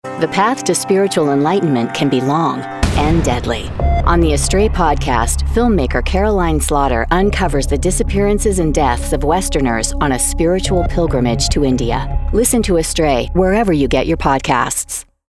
Female
Confident, Cool, Engaging, Friendly, Natural, Warm, Versatile
US General, US West Coast, Canadian West Coast
Microphone: Neumann TLM 103 & Sennheiser 416